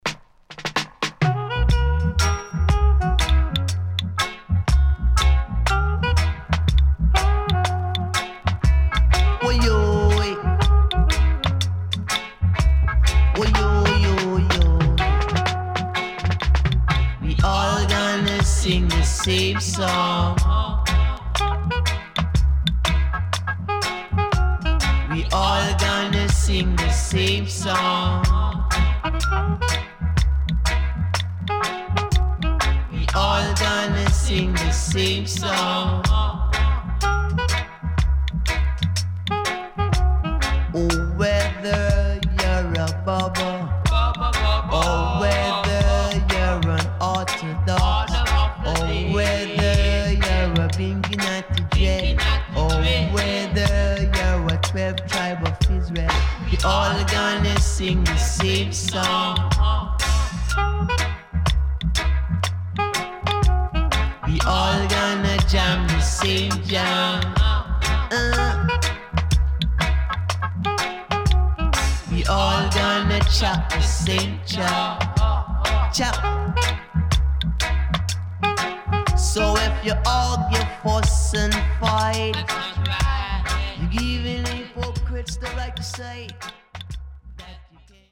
Reissue Used - 【LP】
SIDE A:少しチリノイズ入りますが良好です。